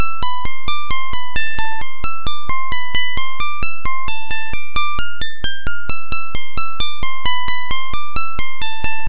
Channels: 2 (stereo)